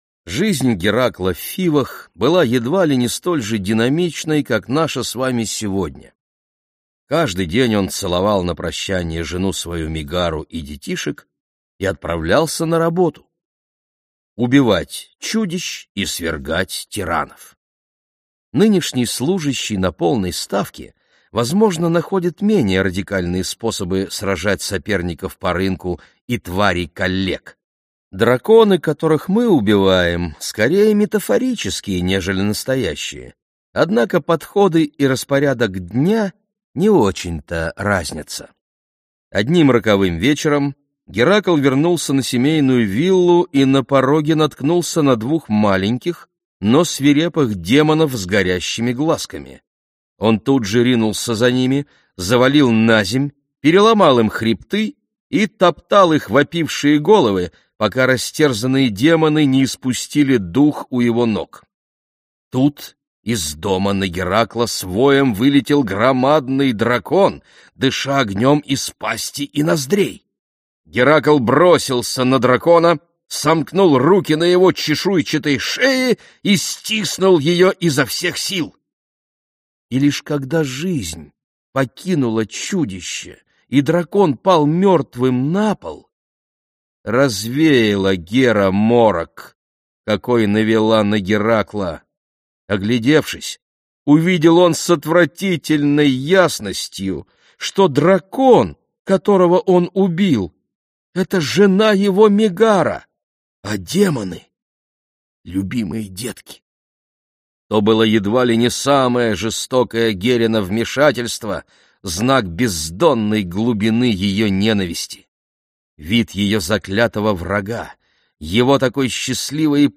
Аудиокнига Герои: Человечество и чудовища. Поиски и приключения | Библиотека аудиокниг